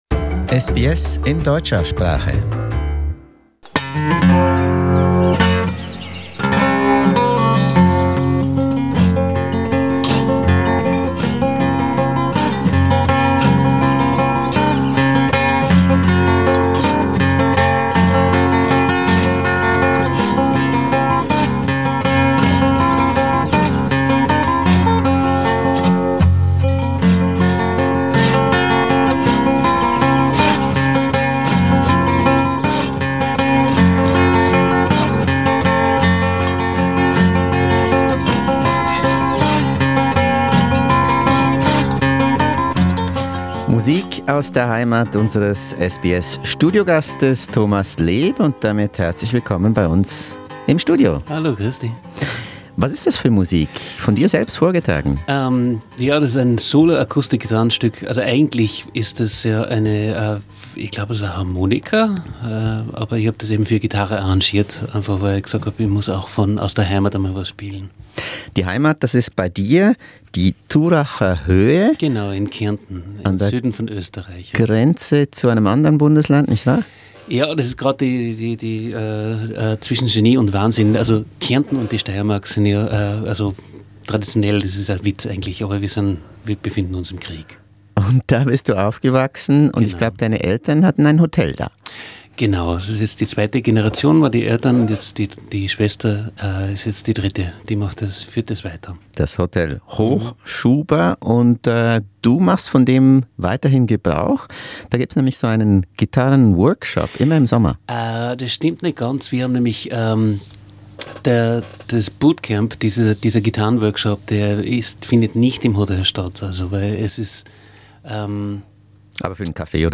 In this interview, he tells SBS about the origins and development of his unique brand of acoustic guitar playing, how he started as a street musician in Europe, why he lives in Los Angeles and why his favourite gig was in Brazil.